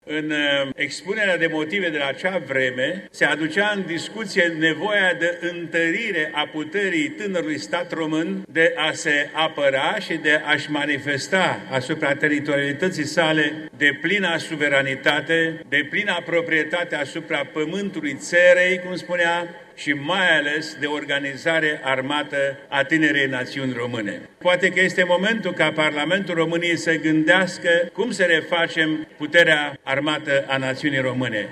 În context, deputatul AUR Mircea Chelaru susţine că sunt necesare măsuri pentru întărirea puterii armate. Într-o intervenţie în plenul Camerei Deputaţilor, el a explicat că această sarcină îi revine Parlamentului, ca şi acum 160 de ani, când a fost votată legea pentru organizarea puterii armate a Principatelor Române: